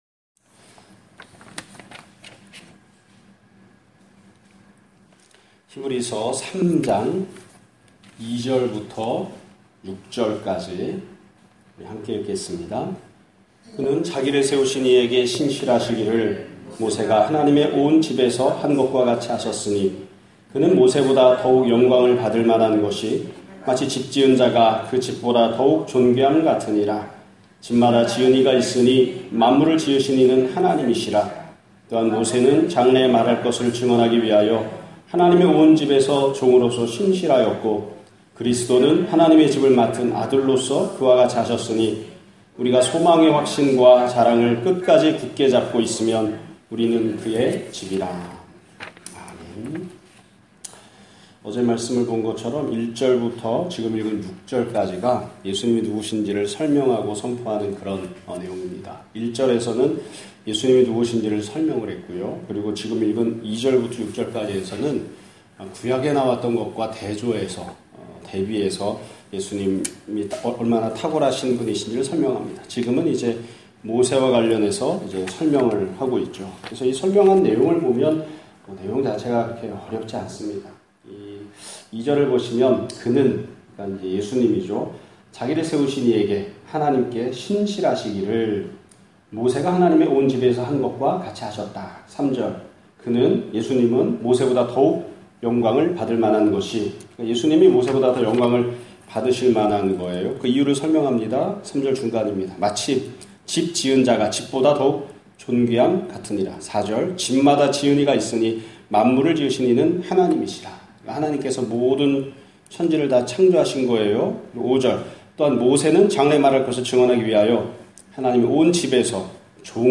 2017년 8월 22일(화요일) <아침예배> 설교입니다.